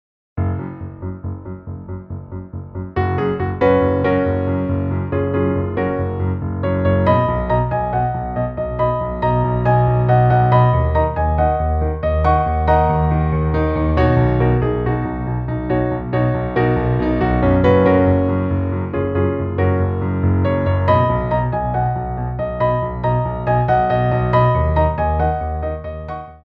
2/4 (16x8)